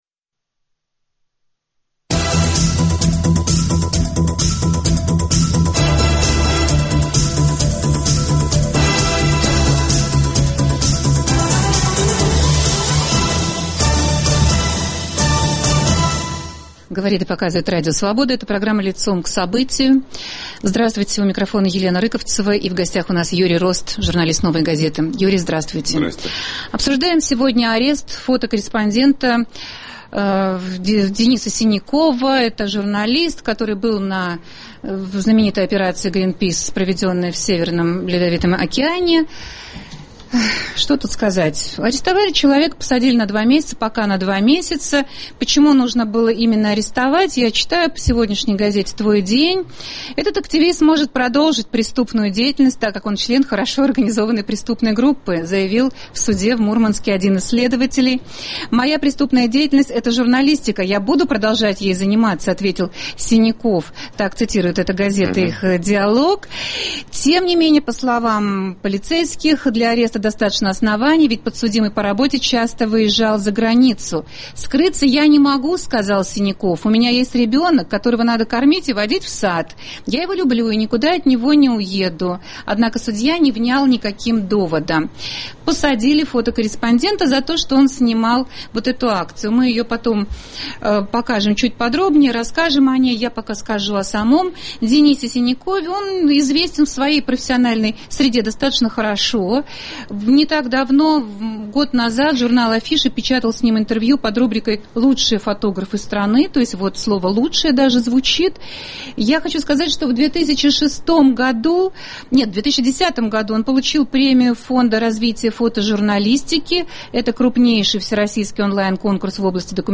Как могло случиться, что за решетку в РФ можно попасть за одно лишь выполнение профессионального долга? В прямом эфире программы - журналист "Новой газеты" Юрий Рост.